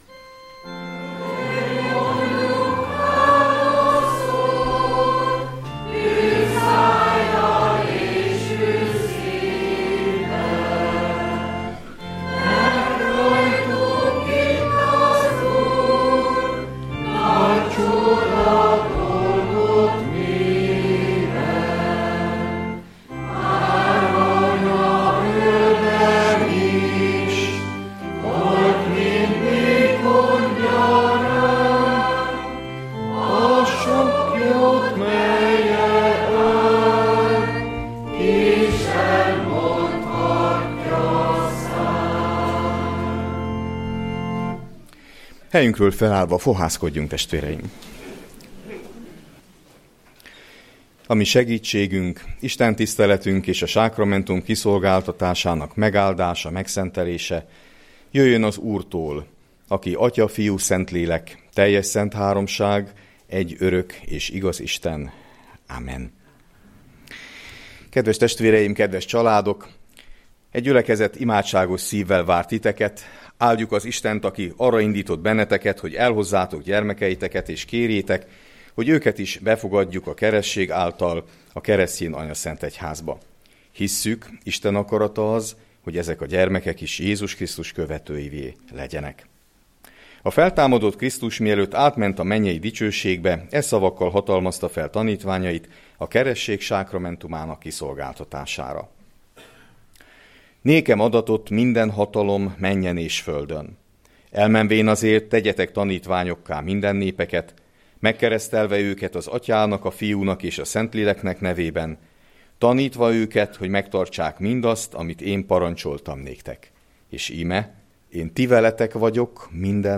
Keresztelő (SL) + a Nagyvárad-Újvárosi Református Egyházközség küldöttségének szolgálata